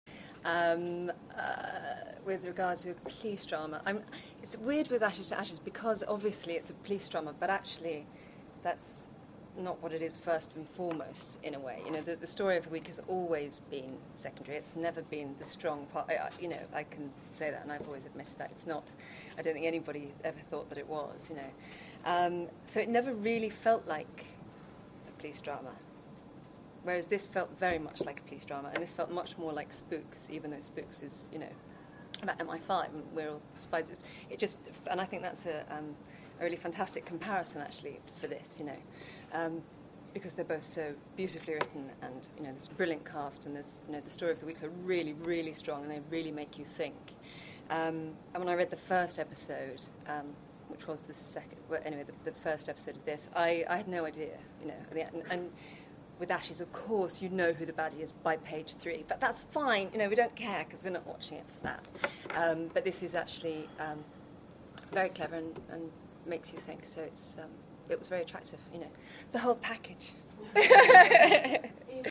I was at a recent press conference for Identity where Keeley was asked about choosing another police role.
identity-press-conf3.mp3